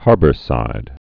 (härbər-sīd)